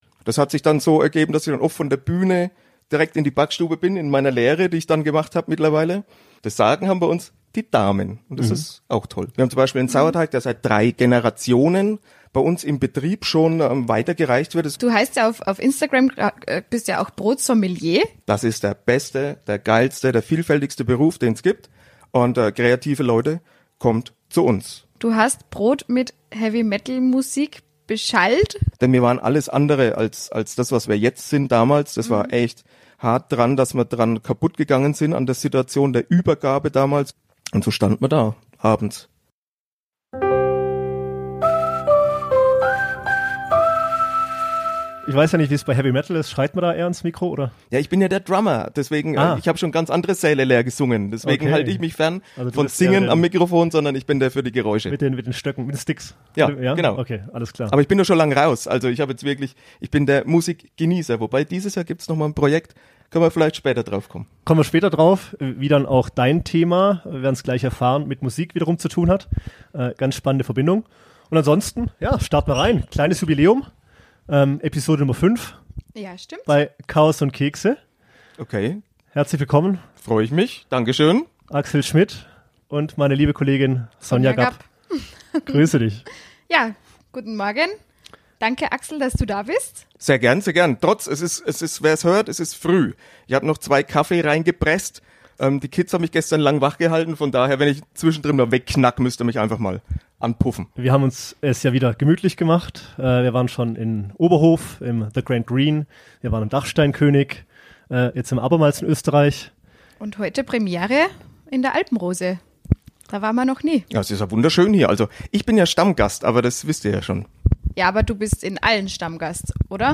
Dies und mehr in der neuesten Episode, zum ersten Mal aus der Alpenrose - Familux Resort in Lermoos.